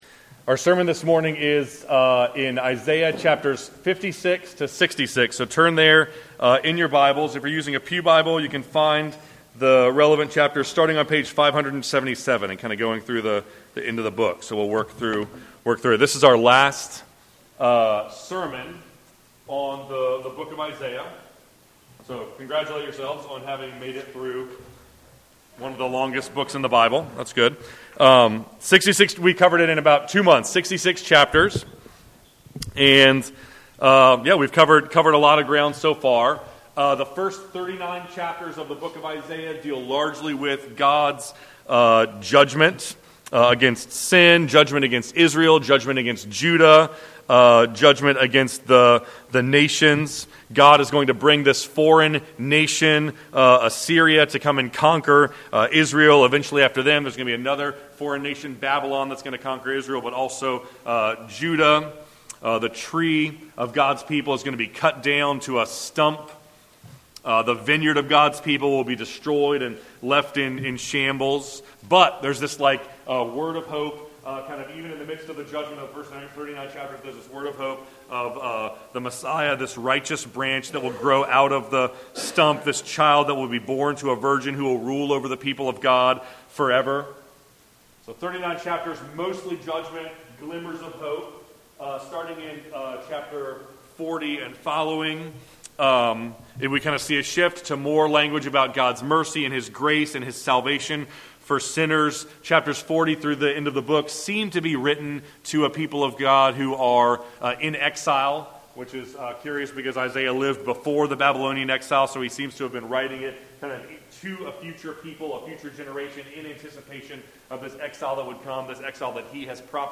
Sermons | James River Community Church